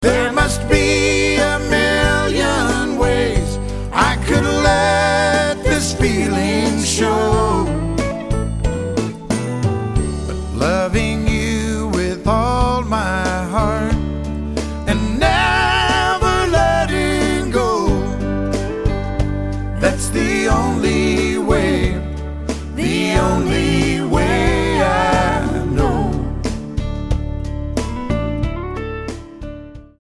STYLE: Country
Almost entirely ballads